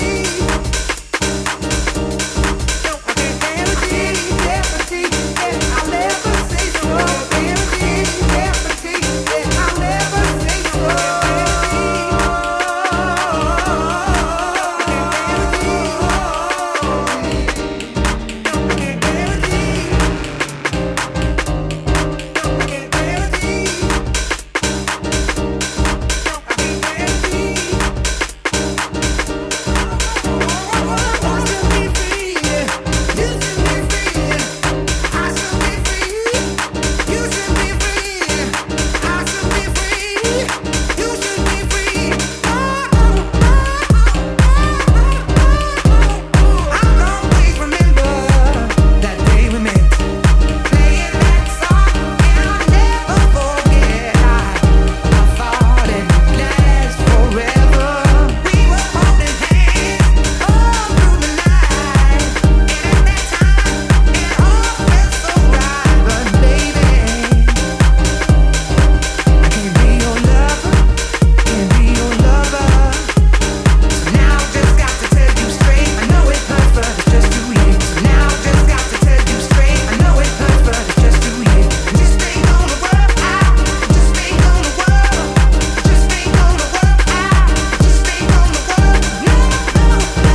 house music
heartfelt and passionate emotional overload